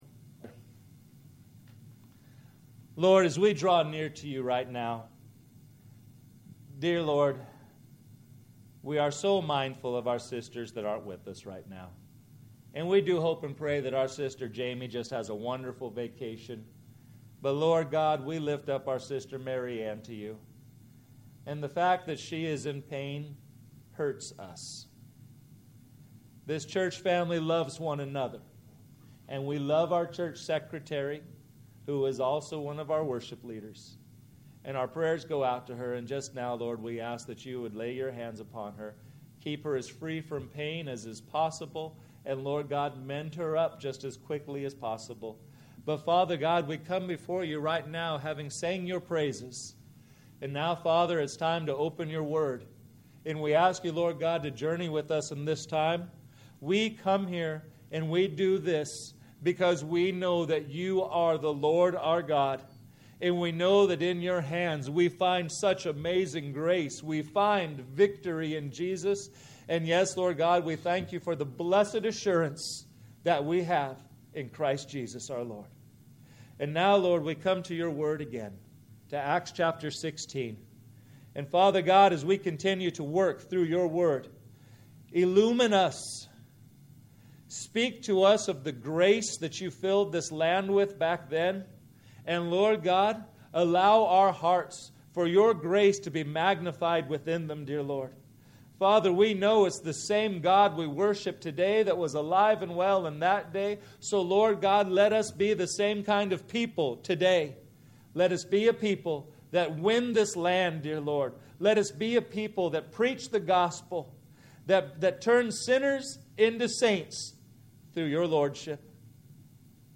Sermons - First Baptist Church Solvang